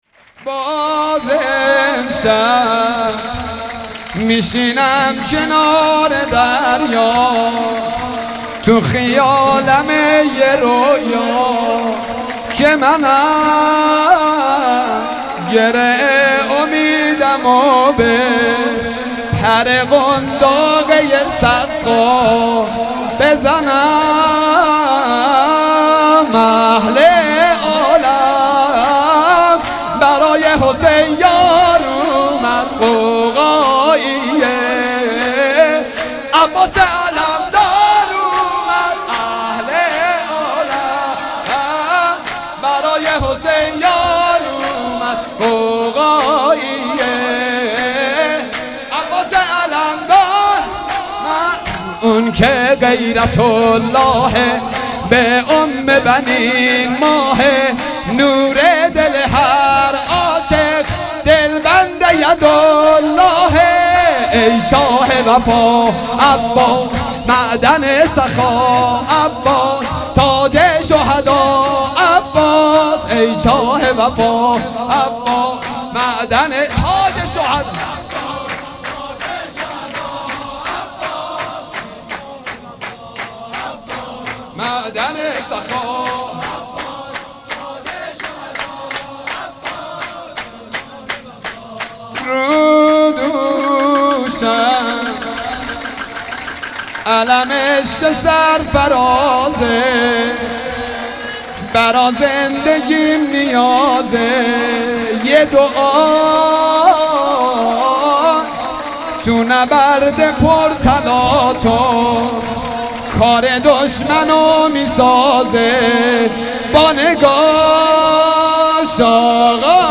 حسینیه بیت النبی